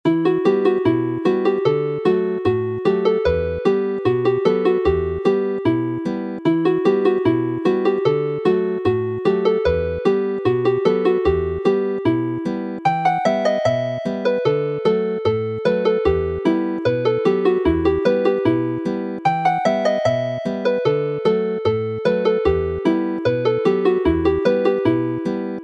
Although it is named as a jig, the music is written and played as a polka.